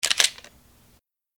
pause-continue-click.wav